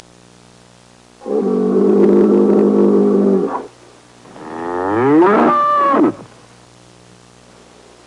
Bull Bellowing Sound Effect
Download a high-quality bull bellowing sound effect.
bull-bellowing.mp3